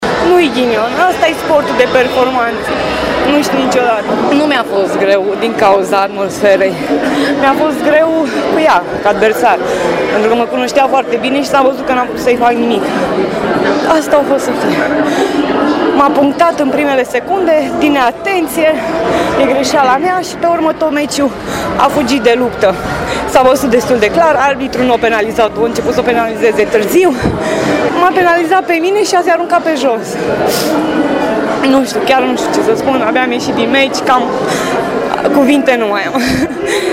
Corina Căprioriu s-a arătat extrem de dezamăgită după ratarea podiumului olimpic. Declarație surprinsă imediat după meciul decisiv